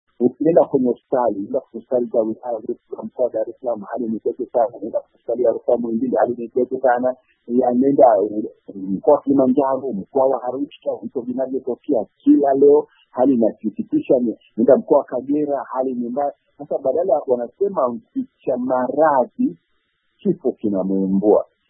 James Mbatia ni kiongozi wa upinzani ambaye amekuwa miongoni mwa wanasiasa wa kwanza kutamka hadharani kwamba kuna ishara nyingi za kuwepo corona Tanzania
COVID-19 : Maoni ya Mbatia